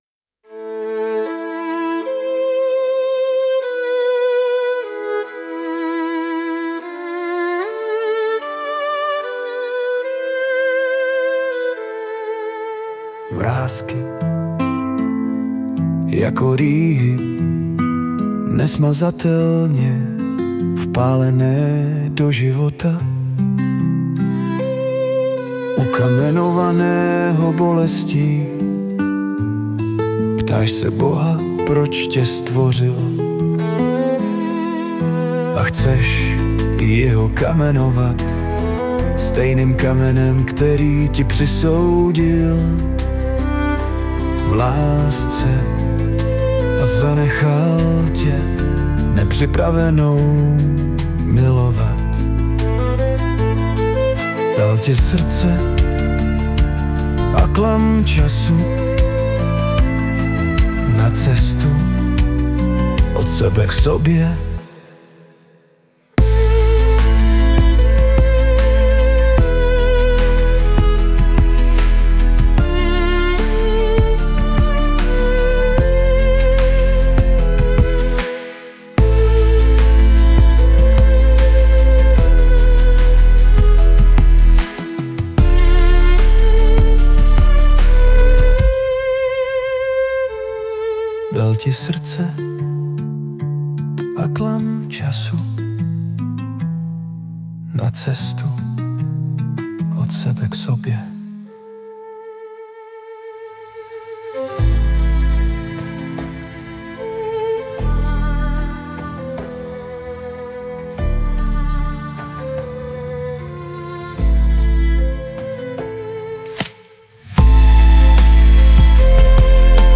2017 & Hudba, Zpěv a Obrázek: AI
už první tóny smyčců mne uhranuly